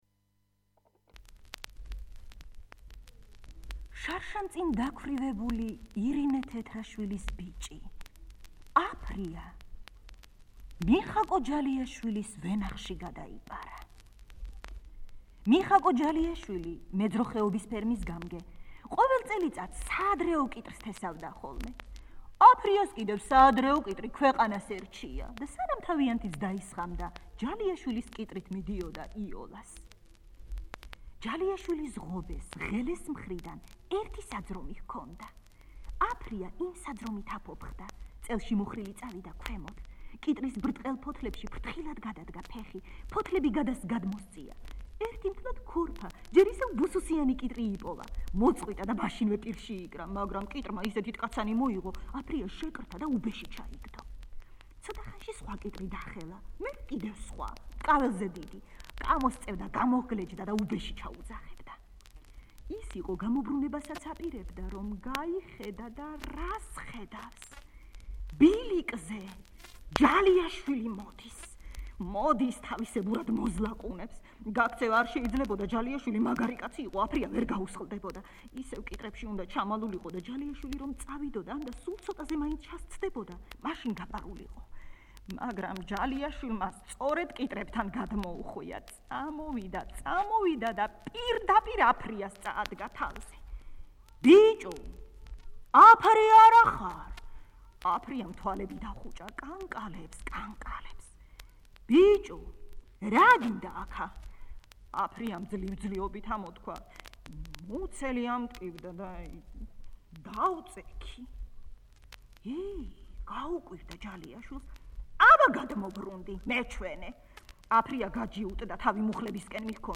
3. ეროვნული ბიბლიოთეკის აუდიო წიგნები